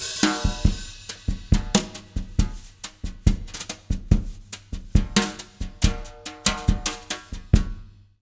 140BOSSA03-L.wav